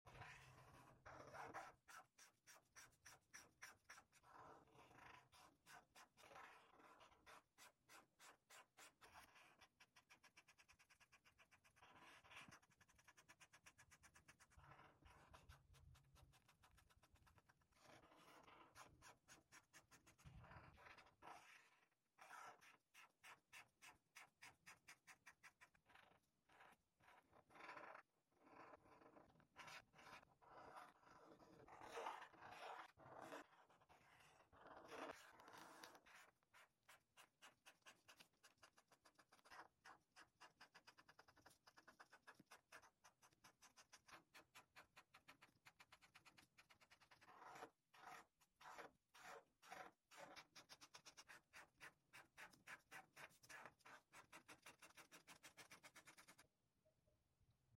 Color With Me! No Talking Sound Effects Free Download
ASMR Acrylic Marker Coloring sound